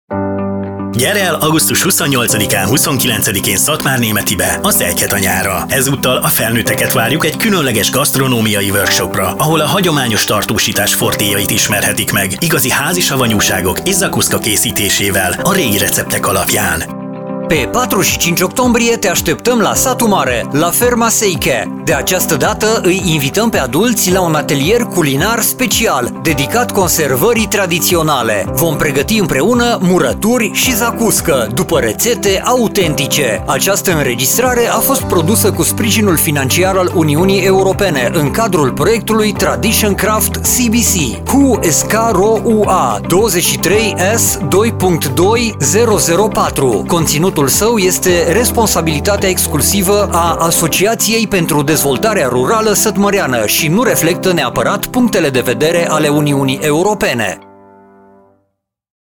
Radio spot - Atelier pentru adulti - Kézműves műhely felnőtteknek